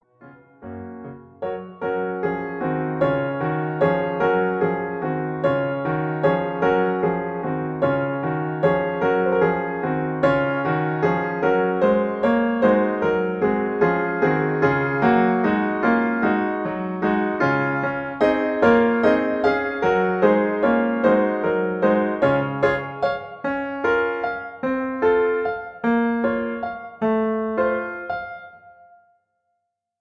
piano acompaniment
in G flat Major